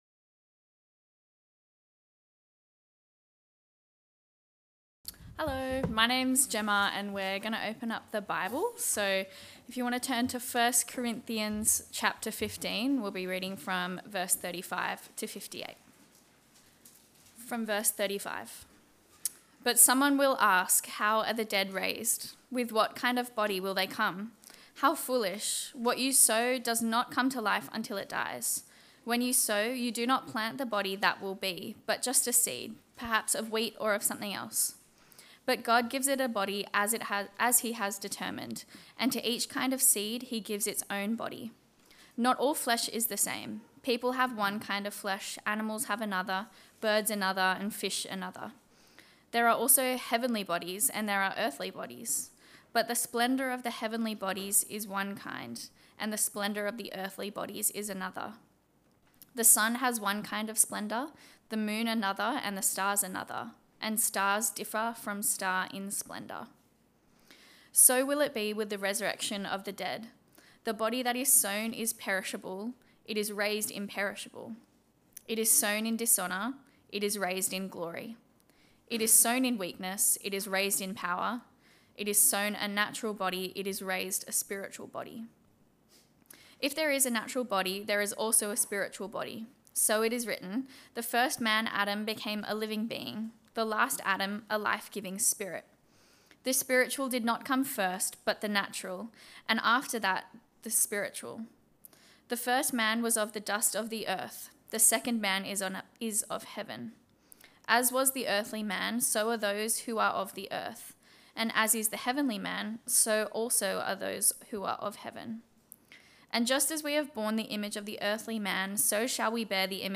Series: We Believe Sermon